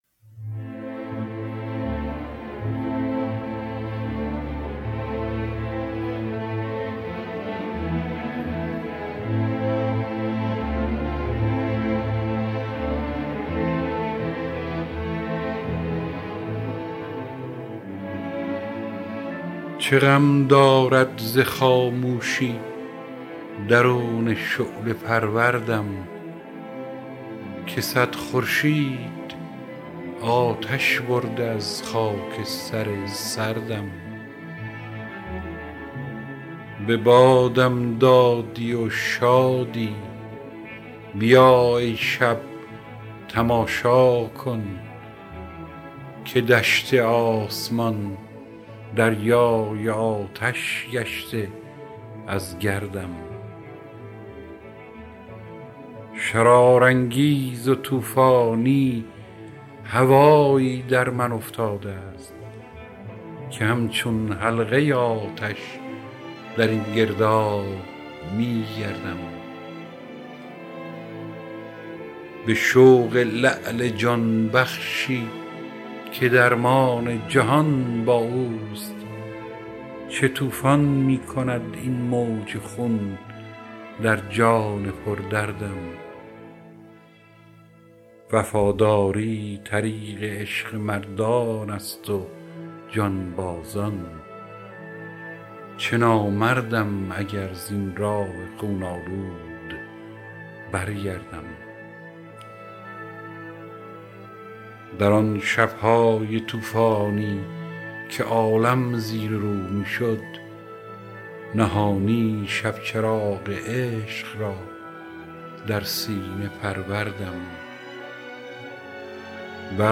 دانلود دکلمه دلی در آتش با صدای هوشنگ ابتهاج
گوینده :   [هوشنگ ابتهاج]